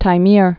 (tī-mîr)